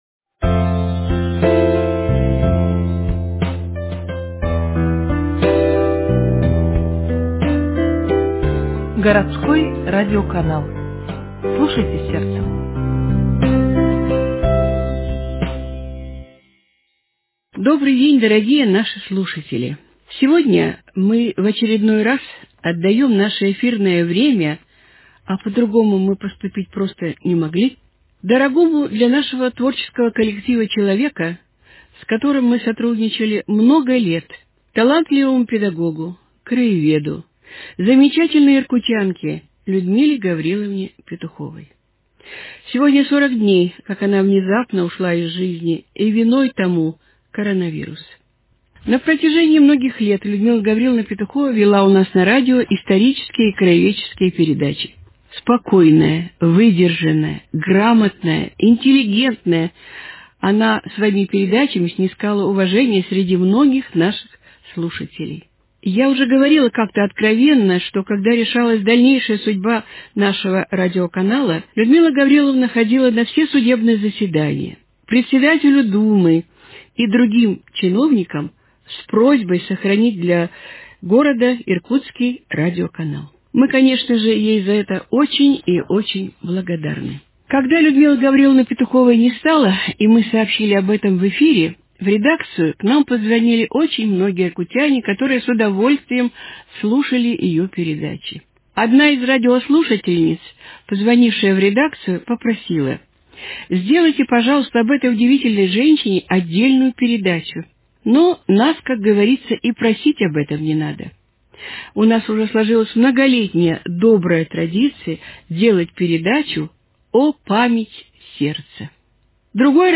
вспоминают ее коллеги, друзья, родные, соседи, ученики, сотрудники редакции подкастов газеты "Иркутск", участники передач и благодарные слушатели.